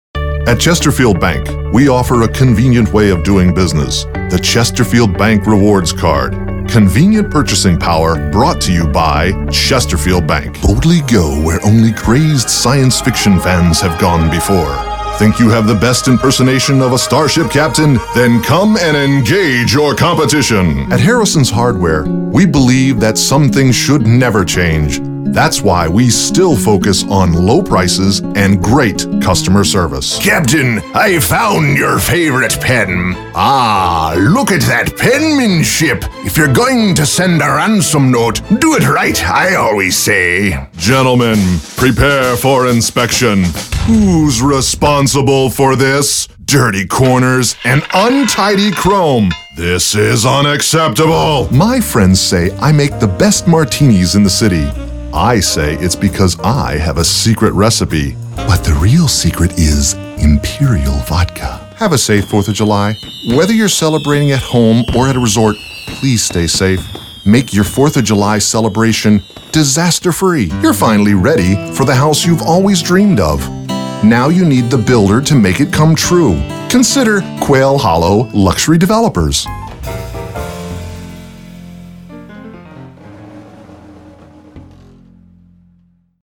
Full-Time Spanish Voice Actor.
1104Commercialdemo.mp3